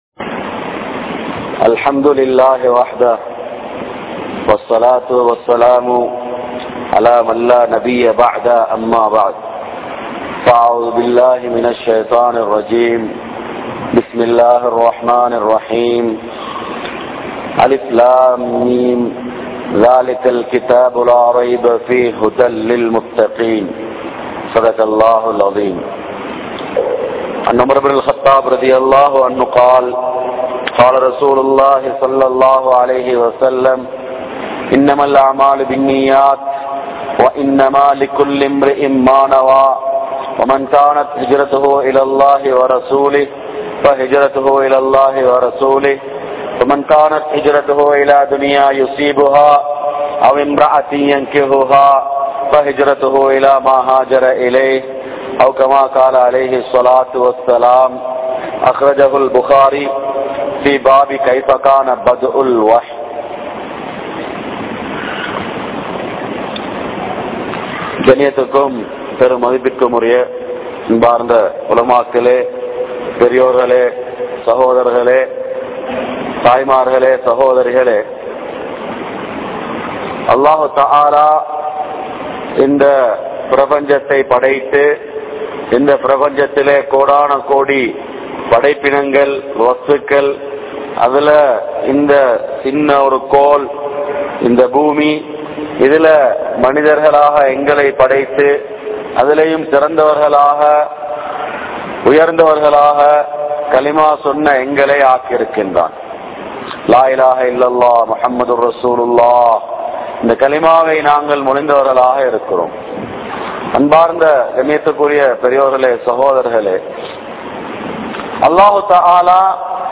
Marumai Vaalkaiku Neengal Thayaaraa? (மறுமை வாழ்க்கைக்கு நீங்கள் தயாரா?) | Audio Bayans | All Ceylon Muslim Youth Community | Addalaichenai
Grand Jumua Masjitth